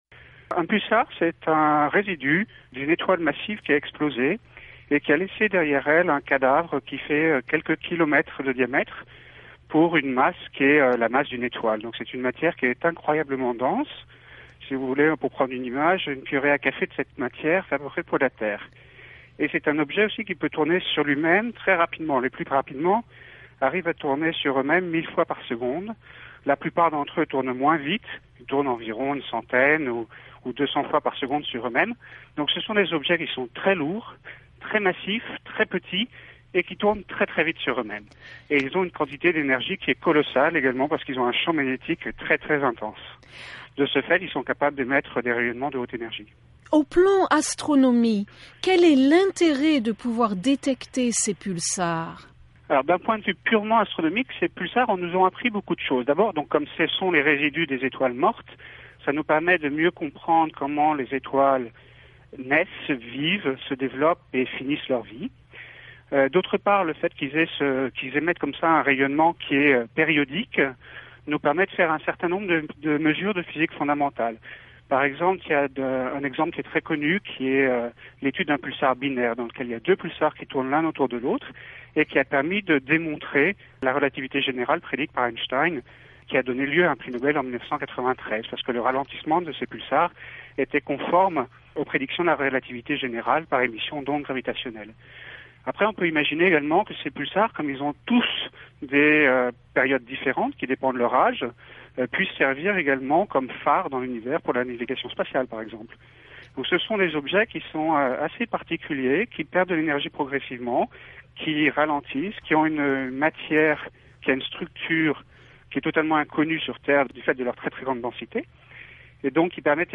« C’est une matière qui est incroyablement dense », a-t-il ajouté dans une interview avec la Voix de l’Amérique (VOA).